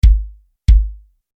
Drumset-Mikrofonierung
Position 1: ...in der Bassdrum, nahe am Schlagfell
Die hier gezeigte Mikrofon-Position in der Nähe des Schlagfells führt zu einem Klangbild, welches deutlich vom "Impuls" des Schlägels dominiert wird.